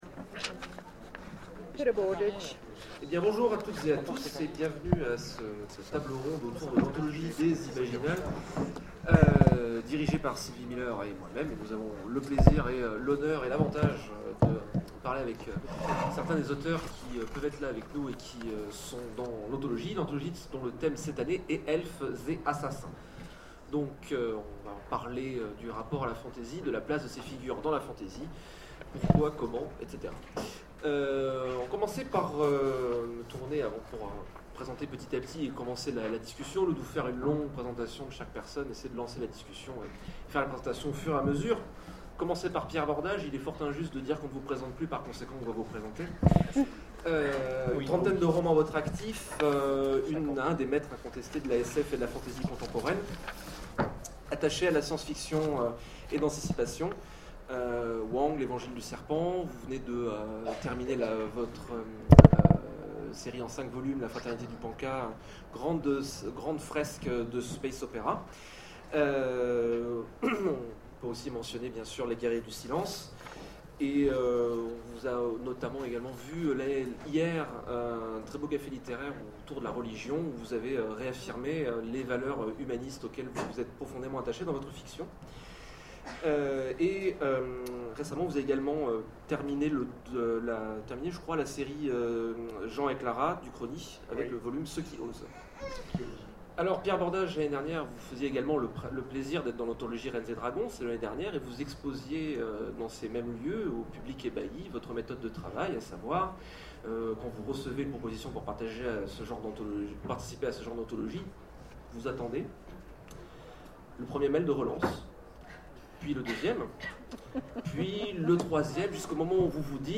Imaginales 2013 : Conférence Elfes & Assassins